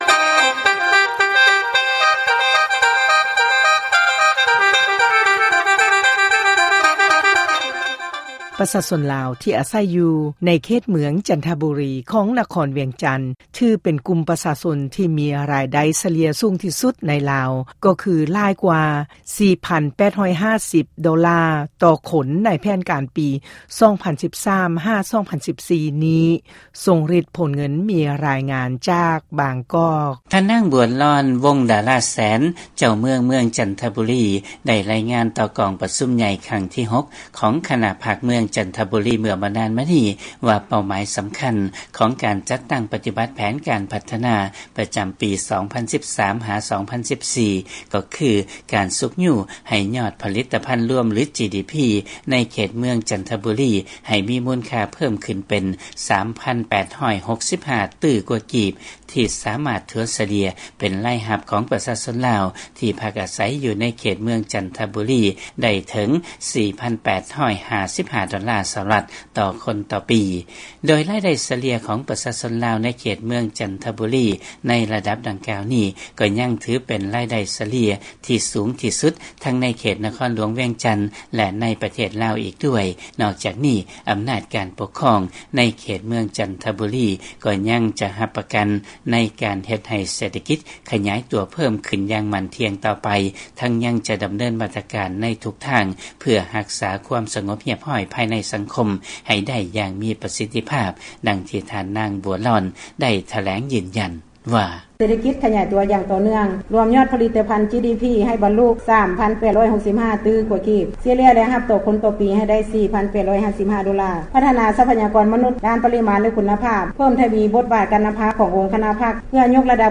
ຟັງລາຍງານເລື້ອງ ຊາວເມືອງຈັນທະບູລີ ໃນນະຄອນຫລວງວຽງຈັນ ມີລາຍໄດ້ສູງສຸດ ໃນລາວ.